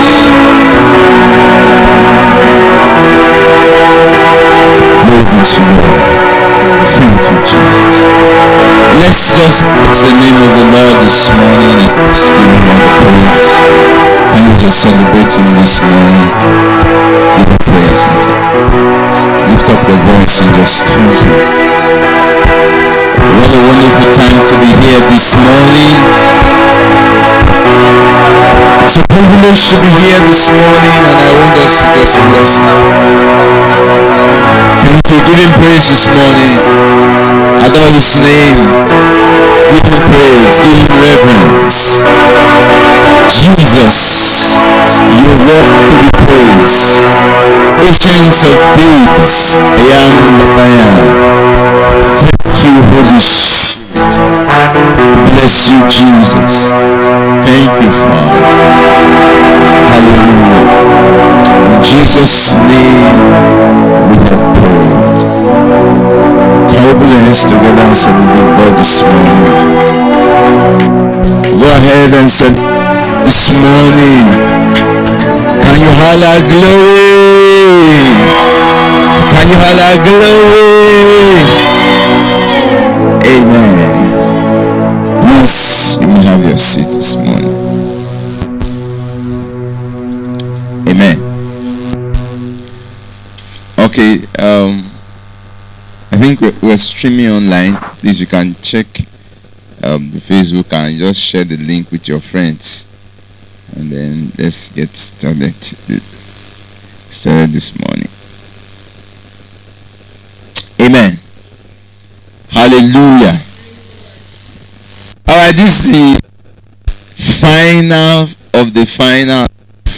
Matthew 8:8 Service Type: Revelation Conference This is the message of faith